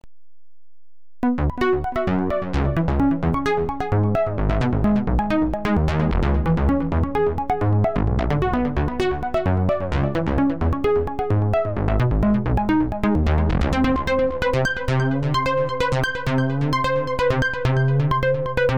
Soft Synth